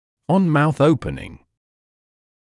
[ɔn mauθ ‘əupənɪŋ][он маус ‘оупэнин]при открывании рта; при открытии рта